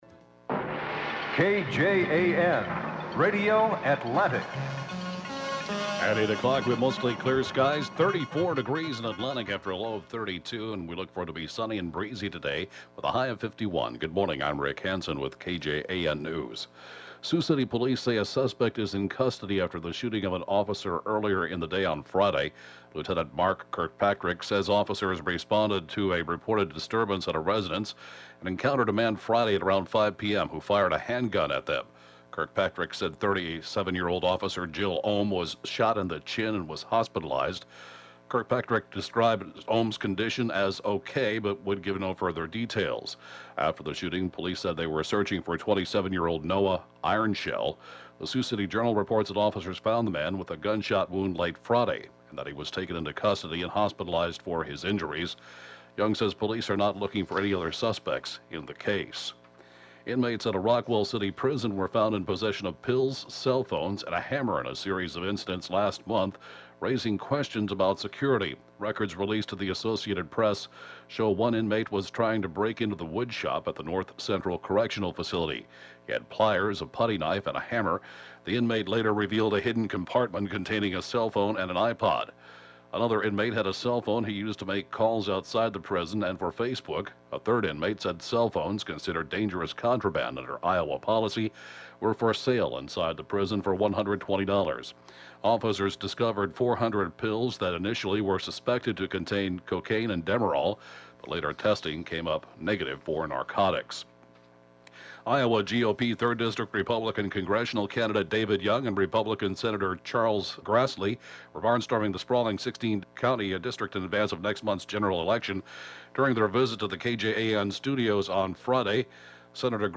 (Podcast) 8-a.m. News, Sat. 10/4/14